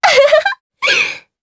Erze-Vox_Happy3_jp.wav